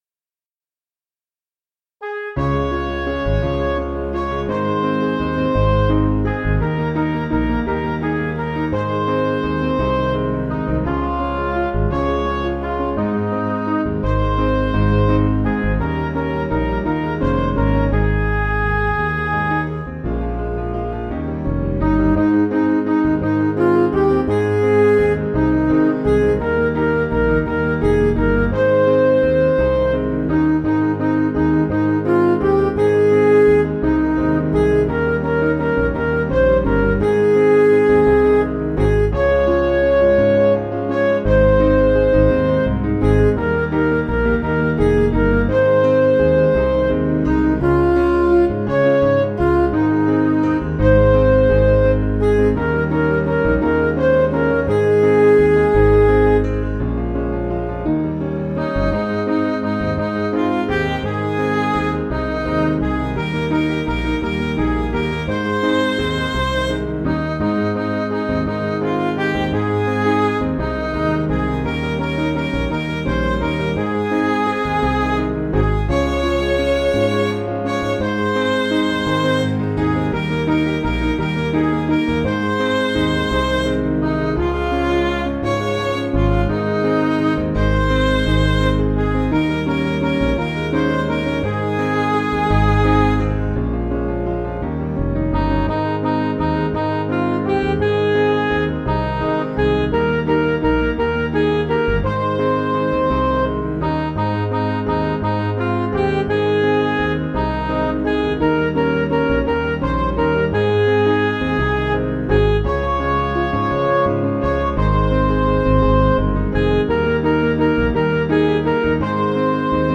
Vocals and Band   263.9kb Sung Lyrics 2.3mb